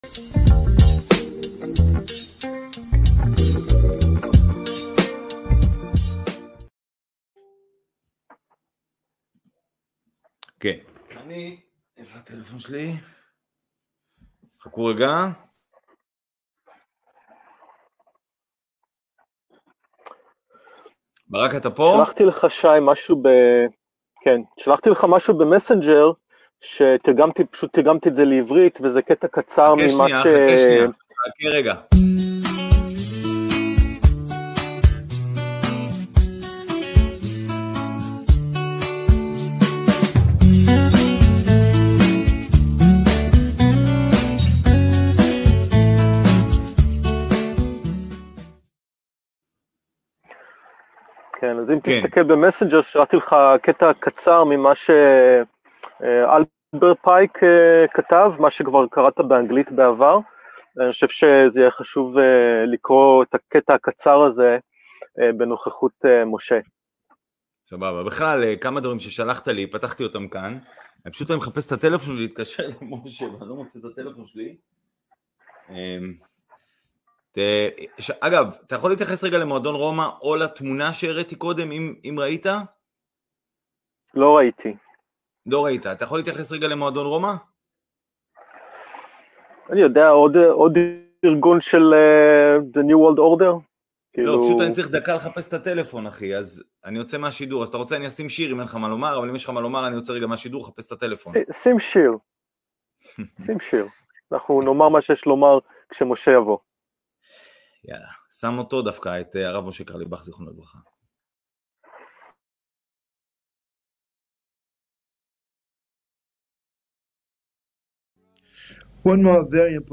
שיחה עם משה פייגלין